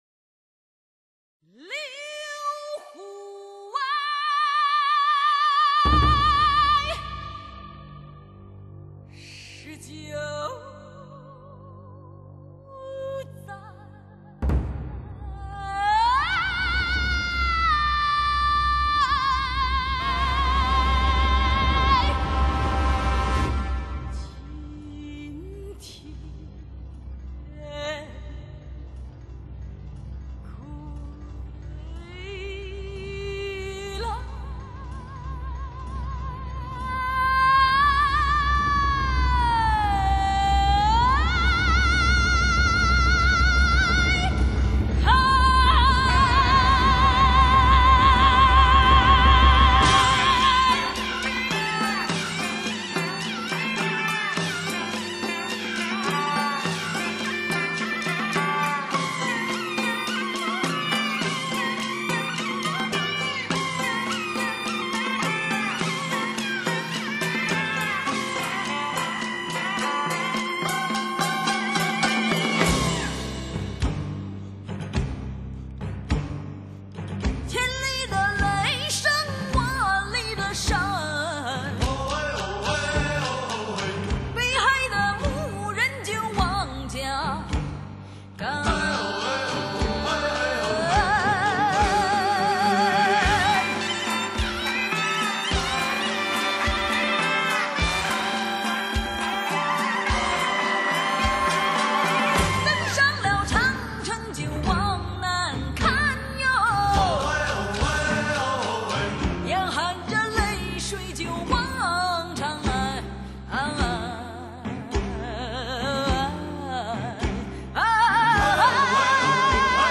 此碟音效极好。
七个段落组成，整部作品气势磅礴，演唱、录音俱佳。
融，蒙古族舞动的节奏与前段形成强烈的对比。
声带着醉意的自由吟唱，表达出苏武奔走在茫茫草原上对春天的喜悦。